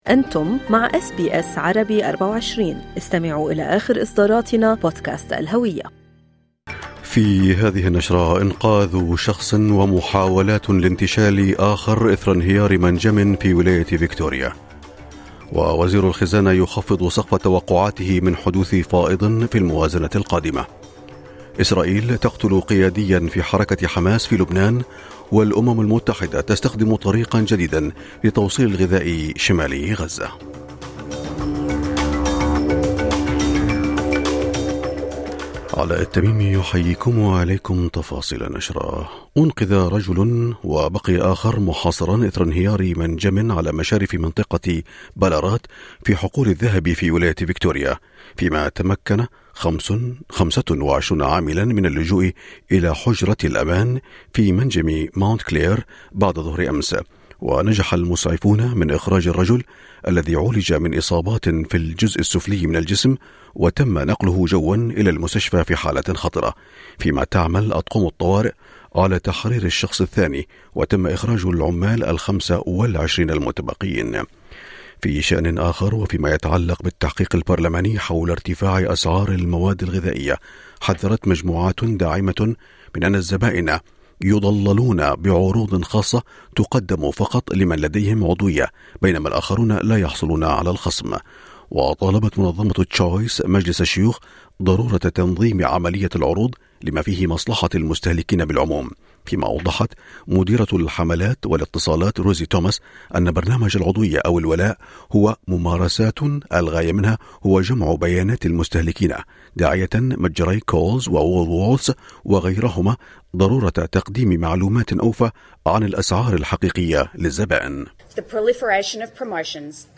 نشرة أخبار الصباح 14/3/2024